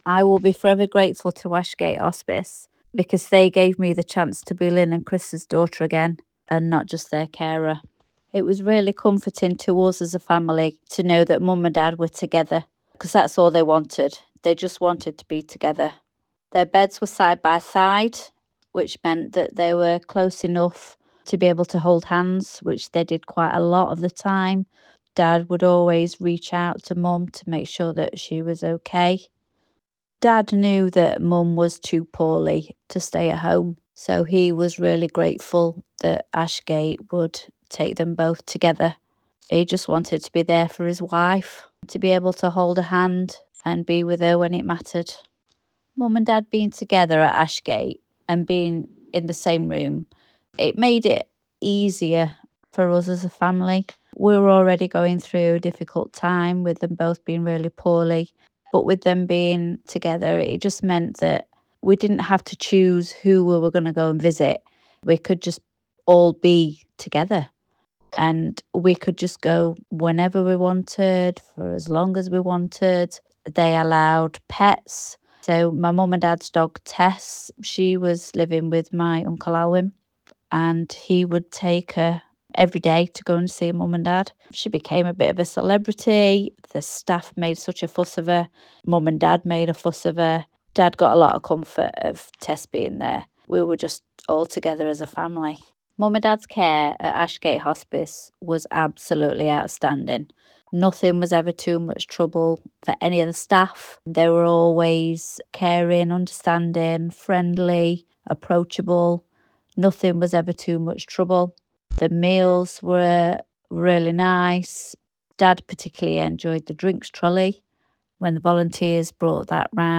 spoke to North Derbyshire Radio about why the hospice means so much to her.